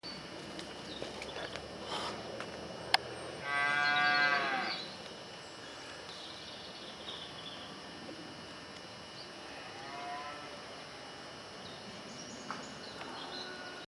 So they are a bit rough and have extra junk in them and are sometimes a bit noisy. (Camera whine.)
23Moo.mp3